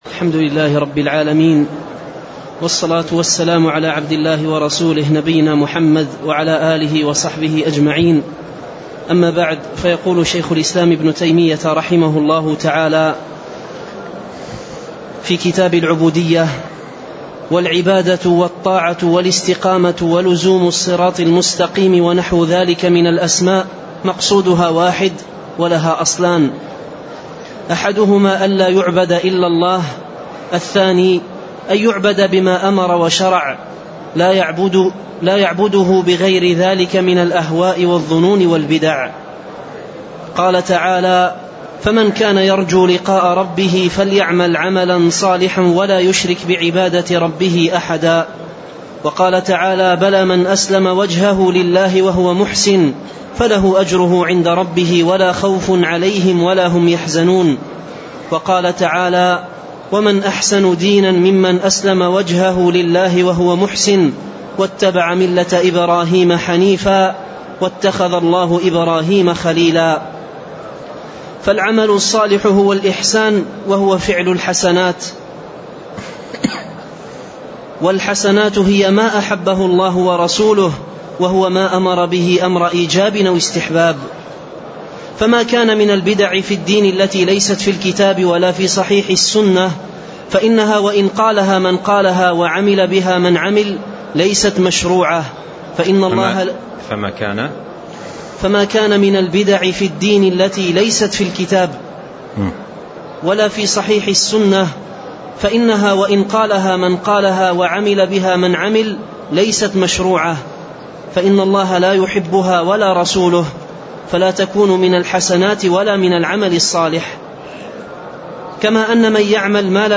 سلسلة محاضرات صوتية،